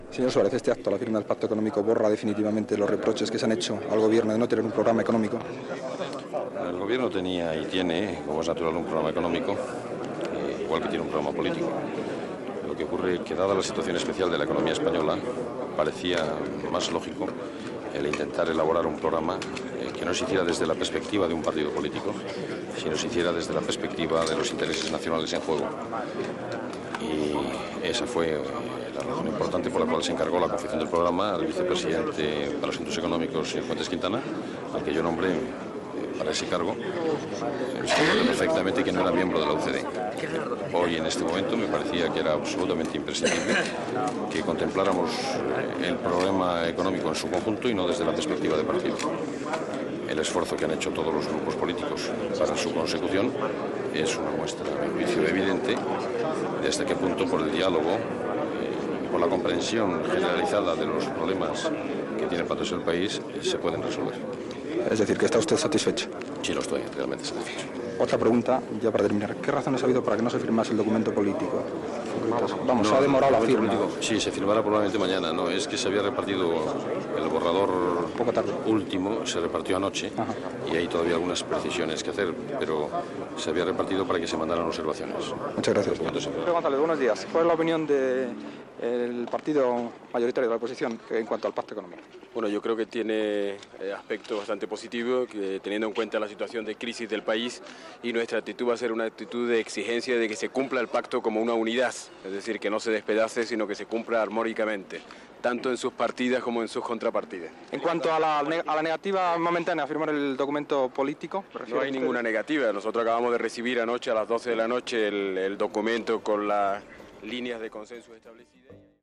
Entrevista al president del Govern espanyol Adolfo Suárez i al líder del Partido Socialista Obrero Español Felipe González després de signar la part dedicada a l'ecnomia dels Pactes de la Moncloa (faltva fer-ho a la part política)
Informatiu
Extret del programa "El sonido de la historia", emès per Radio 5 Todo Noticias el 27 d'octubre de 2012.